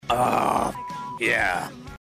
ahhh hcO5mQ2